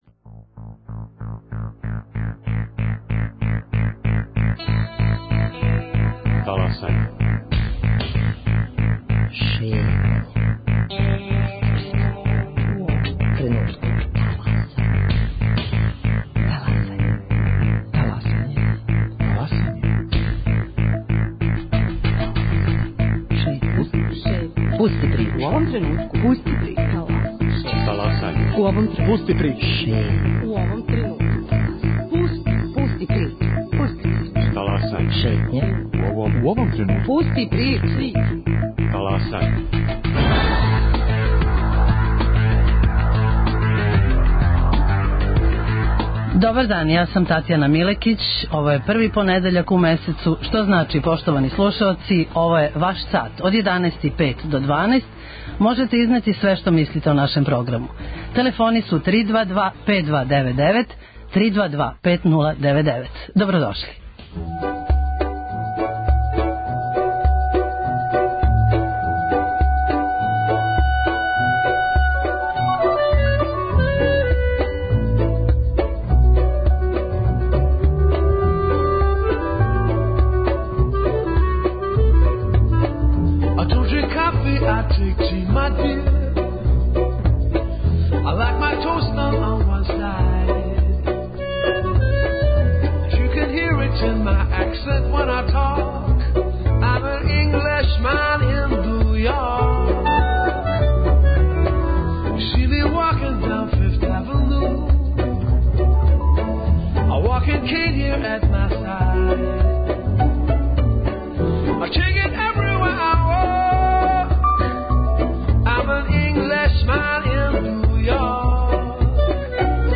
Као и сваког првог понедељка у месецу, и данас вам пружамо прилику да 'таласате' заједно са нама. Ви причате, сугеришете, коментаришете - ми слушамо!